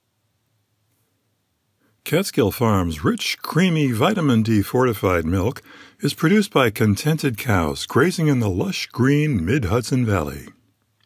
I’m on record insisting that if you have a quiet, echo-free room, you can produce perfectly competent voice tracks on your phone.